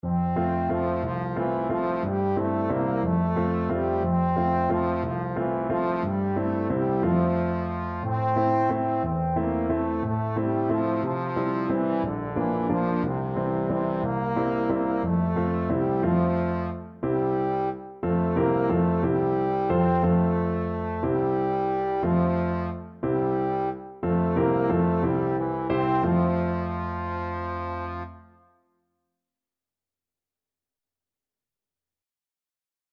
Trombone version
3/4 (View more 3/4 Music)